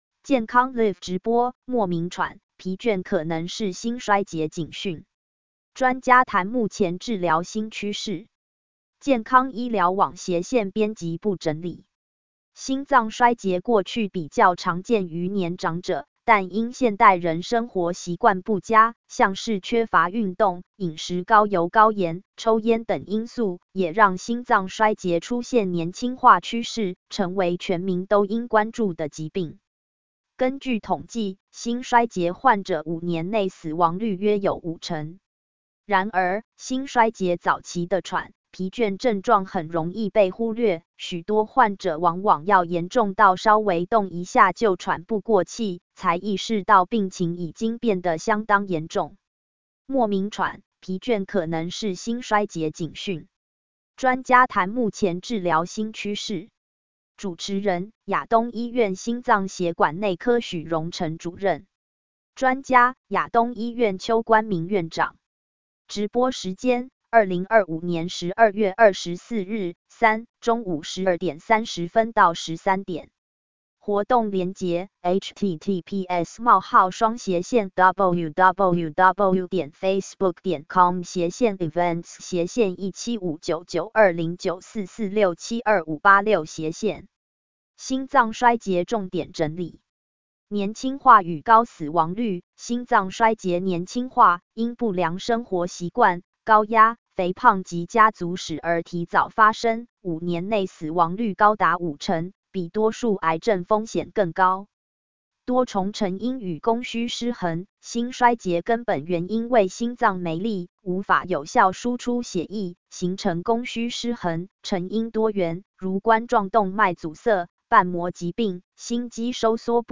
【健康Live直播】莫名喘、疲倦可能是心衰竭警訊？專家談目前治療新趨勢.mp3